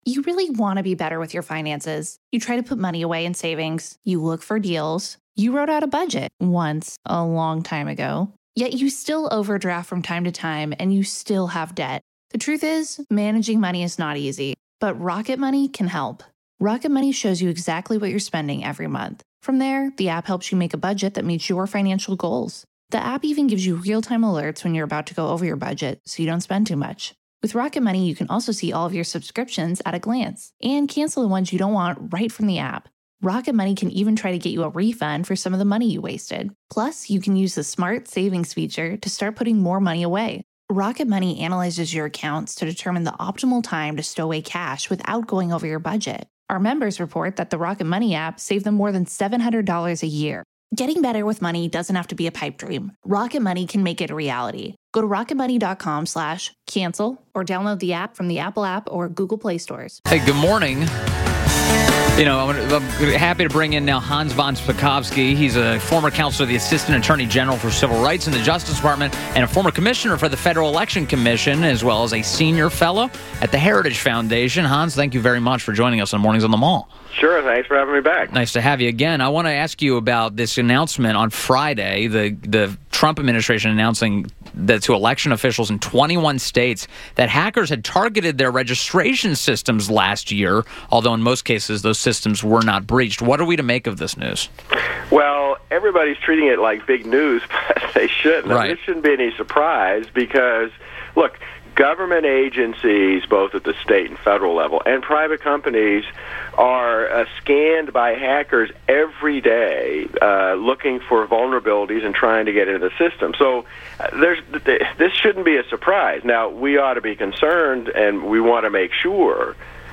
6:35 - INTERVIEW – HANS VON SPAKOVSKY- former counsel to the assistant attorney general for civil rights in the Justice Department, former commissioner for the Federal Election Commission from 2006 to 2007 and senior fellow at the Heritage Foundation